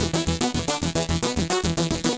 Index of /musicradar/8-bit-bonanza-samples/FM Arp Loops
CS_FMArp B_110-E.wav